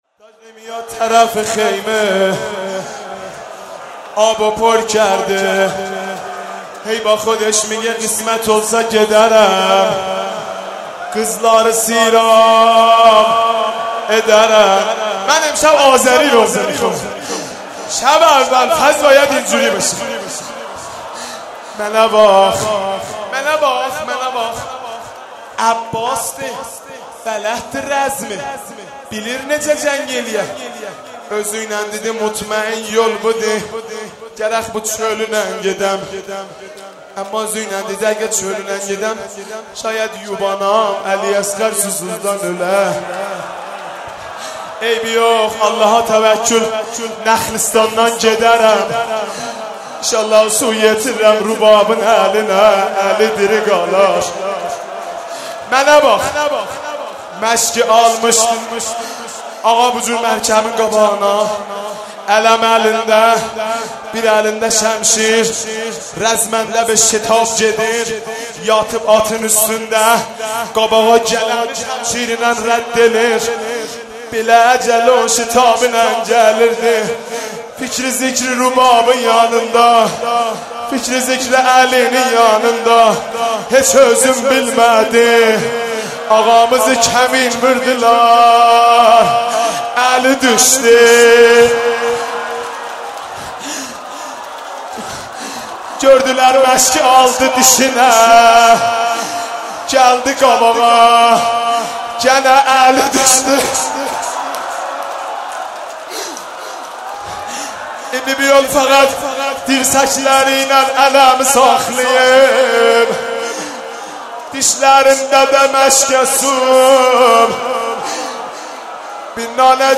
خیمه گاه - هیئت ثارالله(رهروان امام و شهدا) - بخش دوم - روضه (روضه حضرت ابوالفضل علیه السلام)
شب نهم محرم 1394